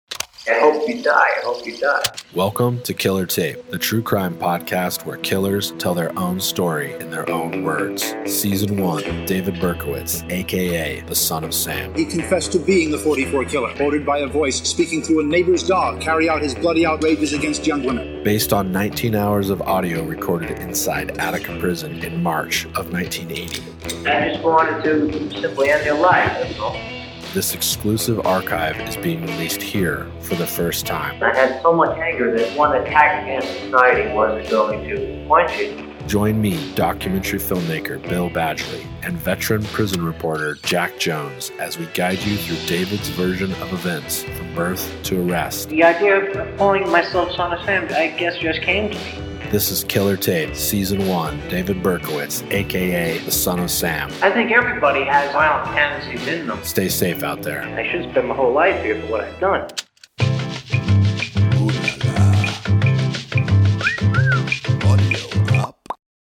Killer Tape is a biographical true crime podcast where killers, and hard core criminals, tell their own story, in their own words.
This exclusive archive represents, not only the most extensive interview ever conducted with Berkowitz, but it is, also his first in depth interview following his arrest, and it is being released here, for the first time.